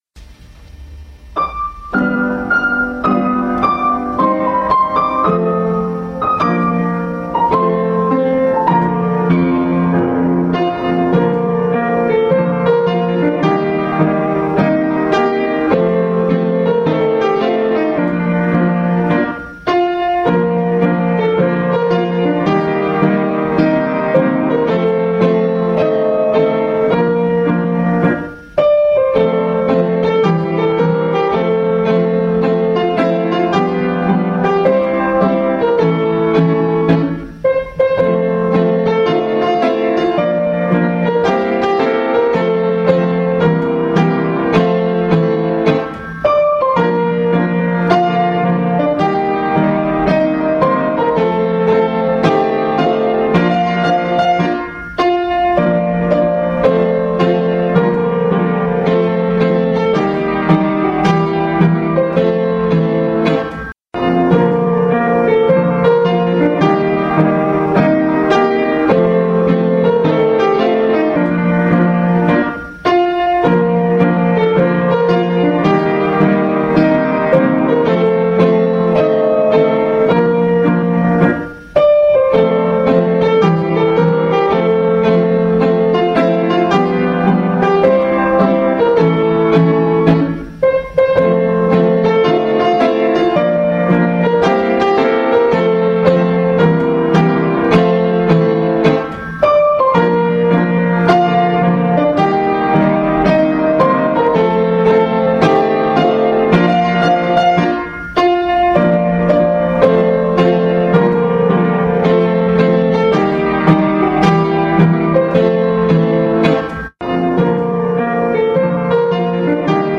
Вступление - три припева с одним куплетом - окончание в последнем припеве.
Сносила сегодня на работу вчерашние записи, выбрали Вашу, потому что звучит на фортепиано мягче, не так грозно.